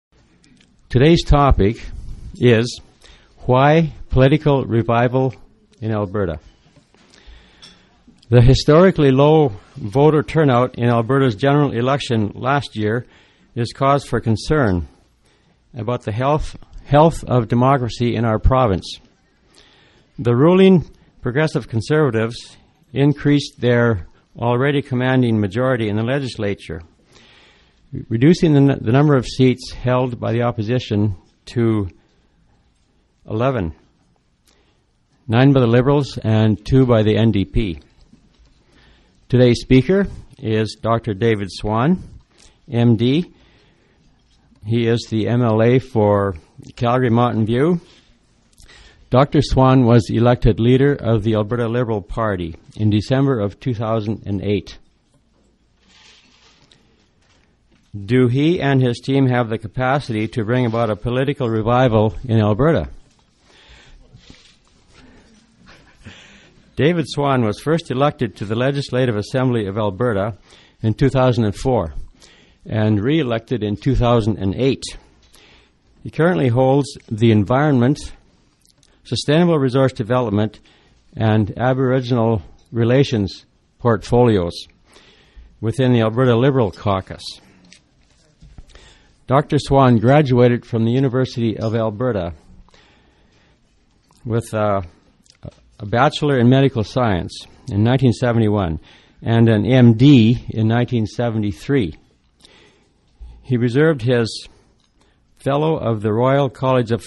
Speaker: David Swann, MLA